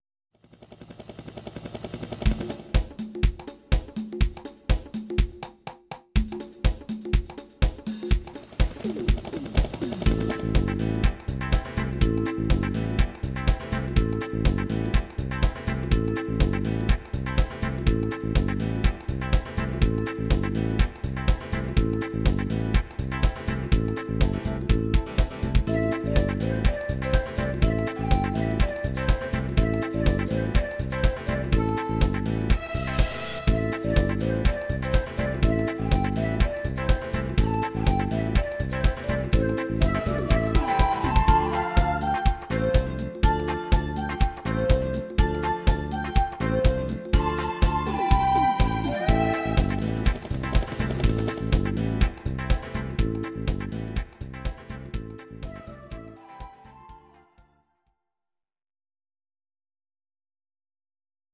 Audio Recordings based on Midi-files
Pop, Disco, 2000s